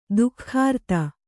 ♪ duhkhārtha